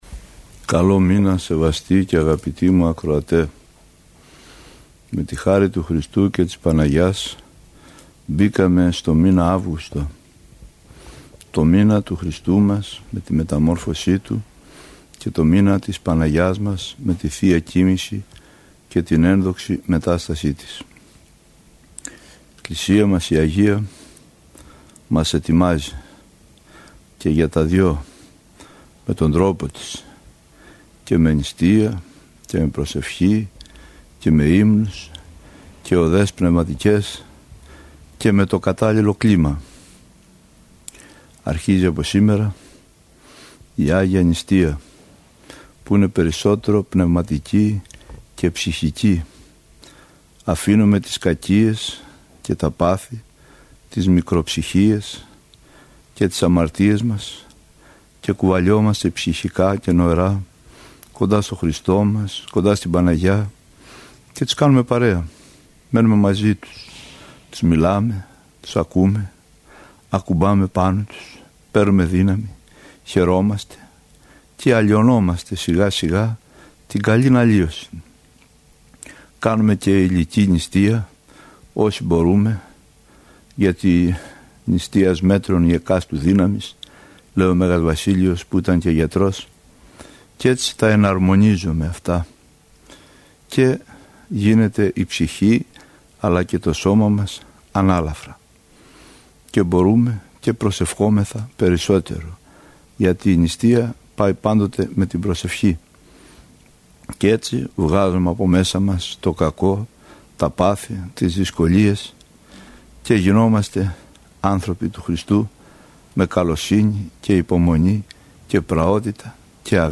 Αρχή του Δεκαπενταυγούστου – ηχογραφημένη ομιλία
Πρόκειται για εκπομπή που μεταδόθηκε από τον ραδιοσταθμό της Εκκλησίας της Ελλάδος, 89,5 FM.